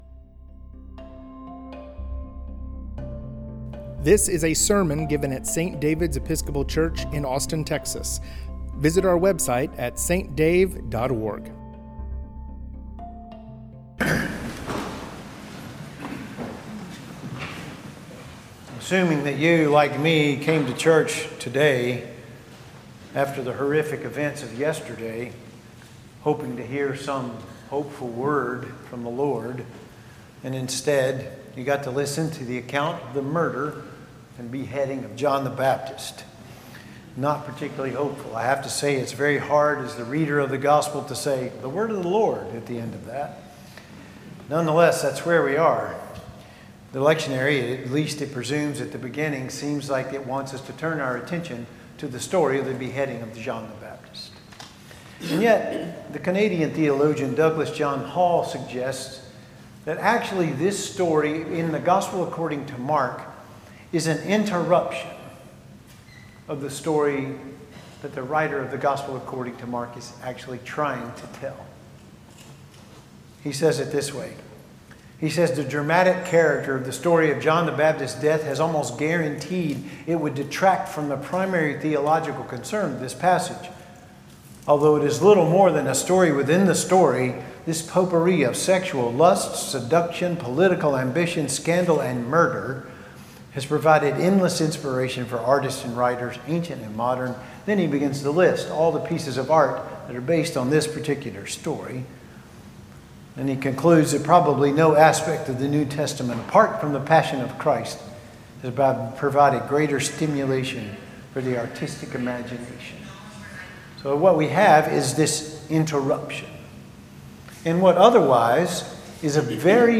sermon from the eighth Sunday after Pentecost
Sermons